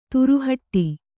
♪ turu haṭṭi